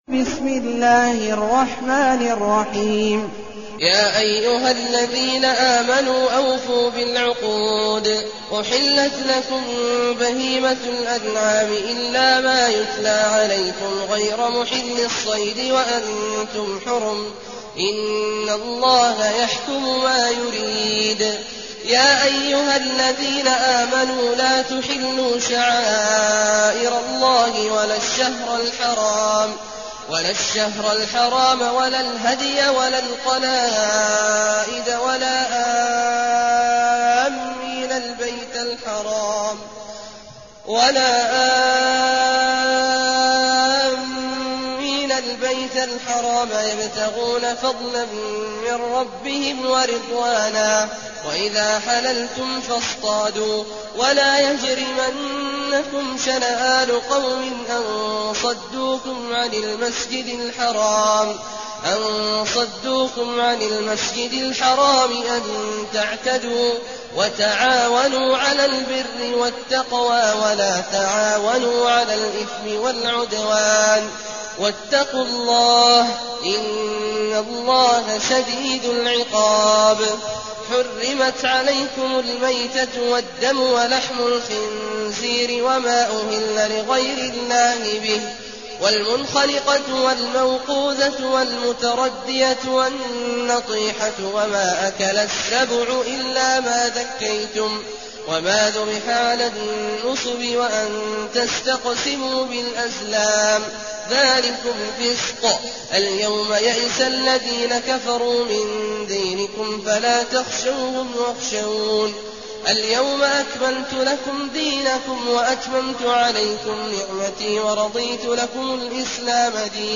المكان: المسجد النبوي الشيخ: فضيلة الشيخ عبدالله الجهني فضيلة الشيخ عبدالله الجهني المائدة